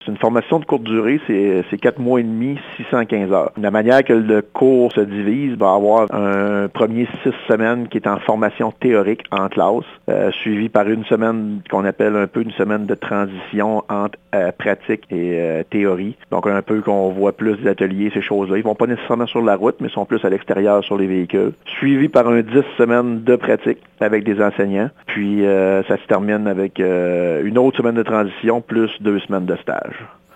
Interrogées samedi dernier, dans le cadre de la visite mensuelle du conseiller municipal Georges Painchaud sur l’île d’Entrée, des citoyennes se désolent que les travaux de maintenance n’aient pas été mieux planifiés.